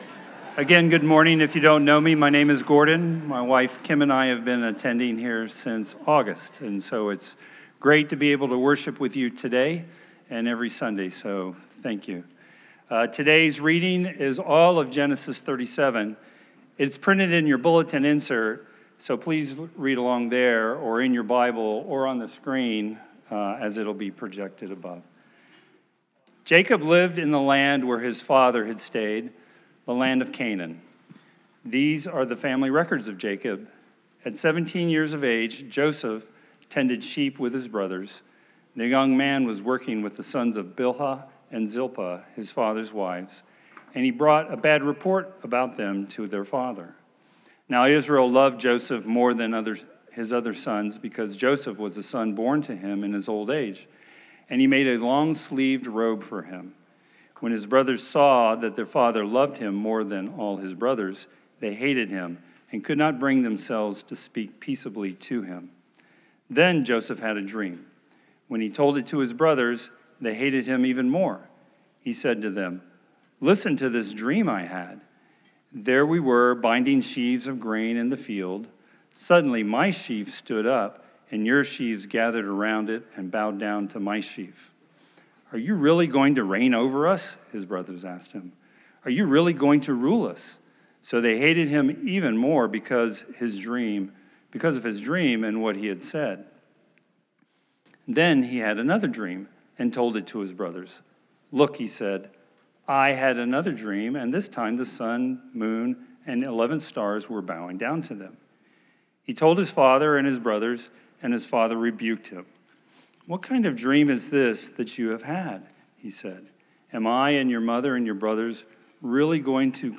God's Broken but Chosen Family Scripture Text: Genesis 37: 1-36 Date: October 12, 2025 AI Generated Summary: In this sermon, we explore Genesis 37 and Jacob's deeply broken family, seeing how sin pervades every relationship through favoritism, pride, and hatred. Yet we're learning that God's graceful providence works mysteriously through all this brokenness, arranging circumstances to bring about good and salvation.